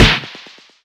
highkicktoe3_hit.wav